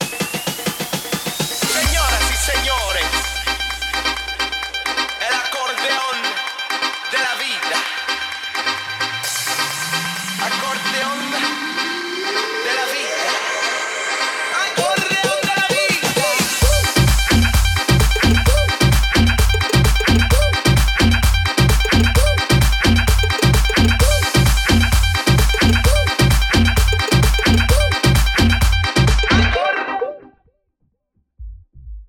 BPM 130 / Guaracha